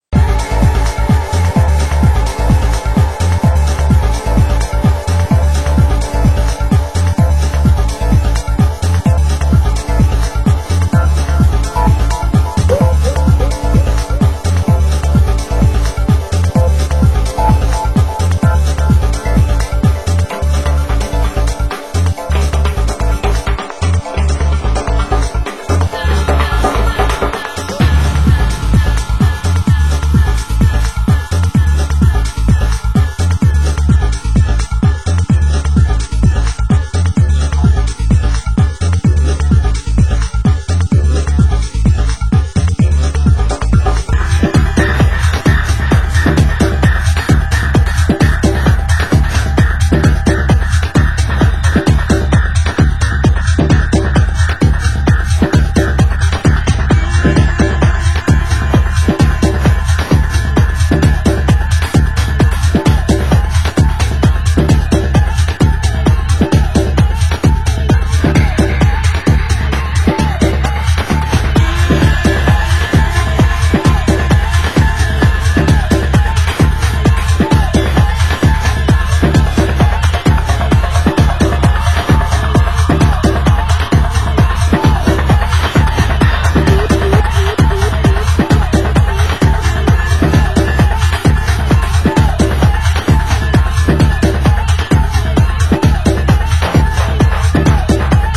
Genre Tech House